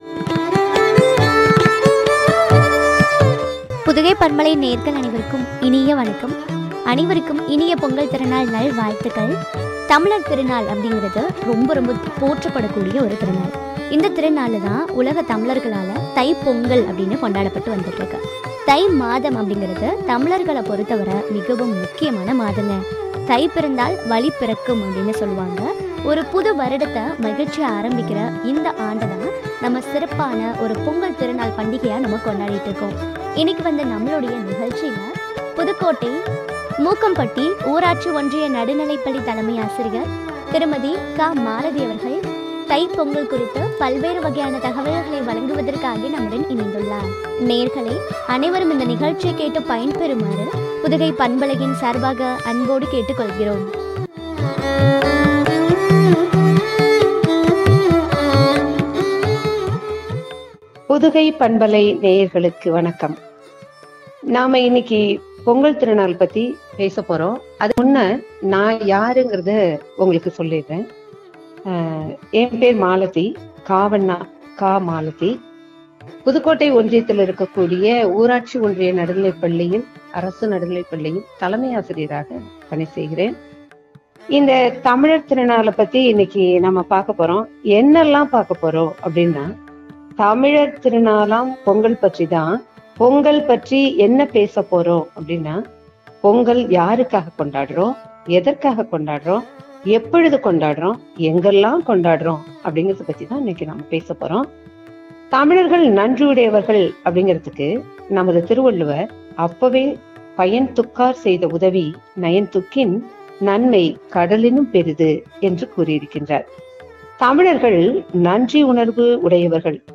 “பொங்கலோ பொங்கல் ” குறித்து வழங்கிய உரையாடல்.